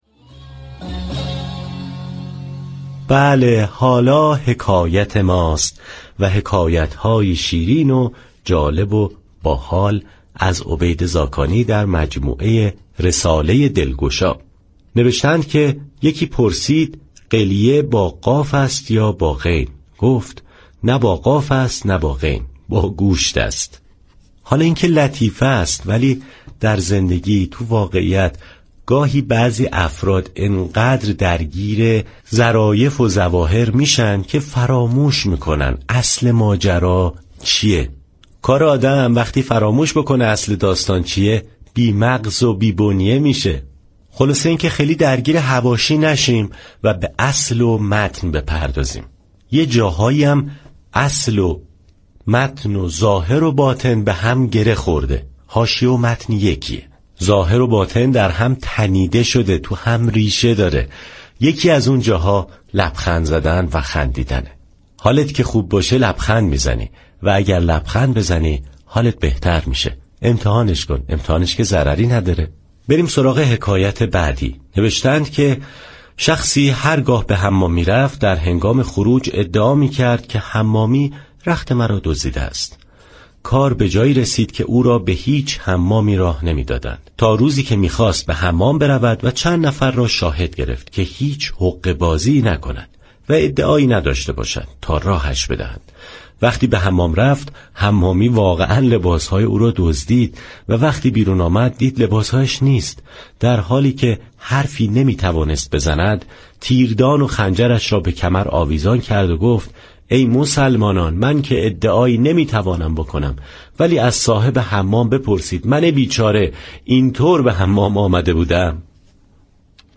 حكایت طنز